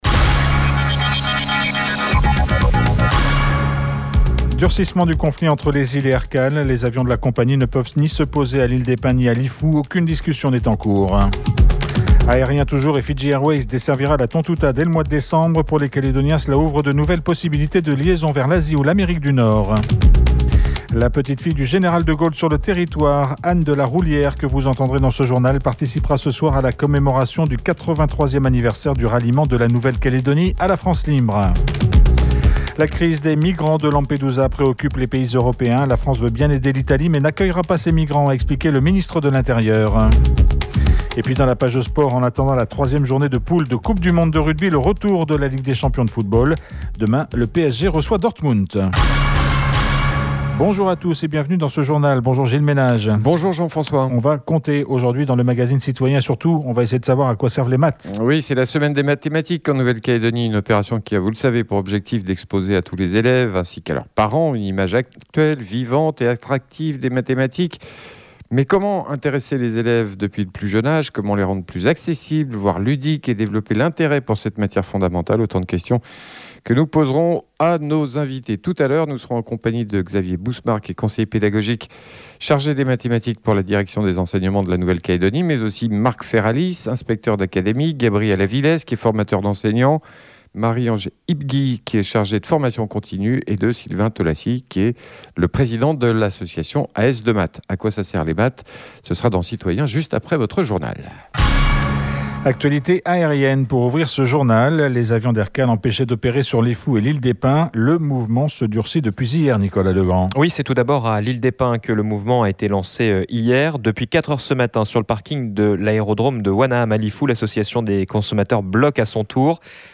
Entretien à suivre dans le journal.